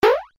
jump.mp3